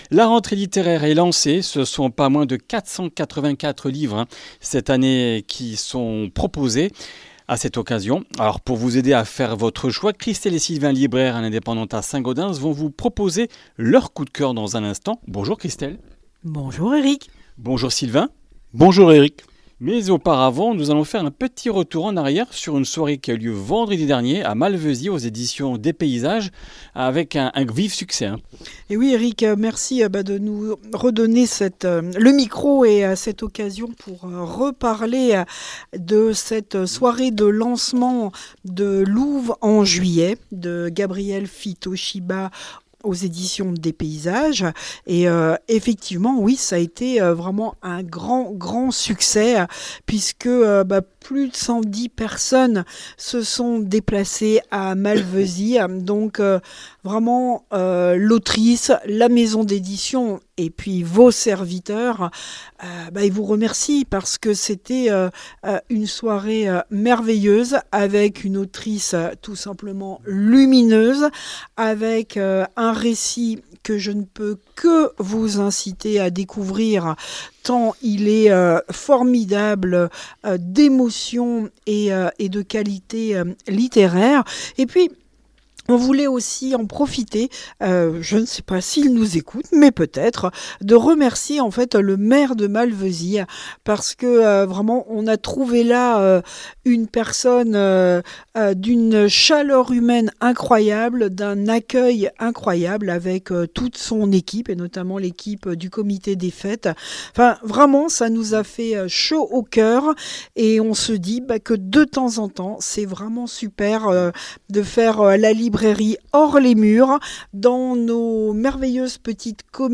Comminges Interviews du 29 août